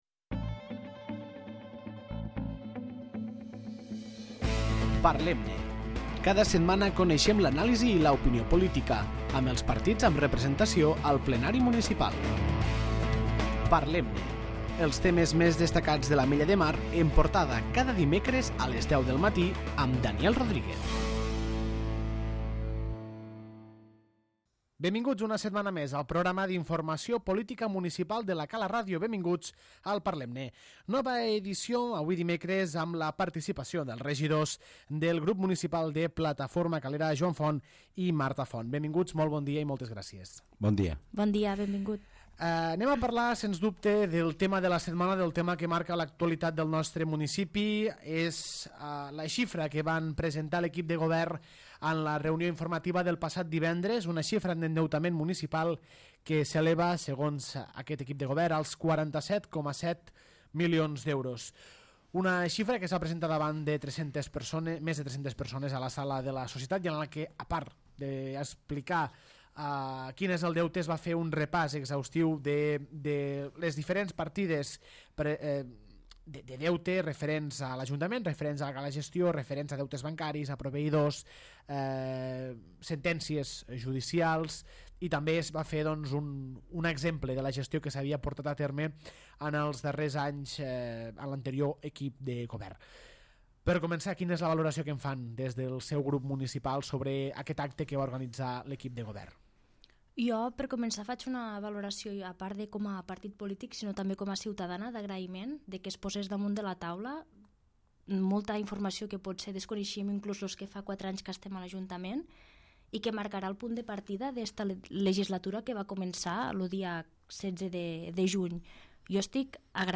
Nova edició del programa, amb la participació de Joan Font i Marta Font, regidors del grup municipal de Plataforma Calera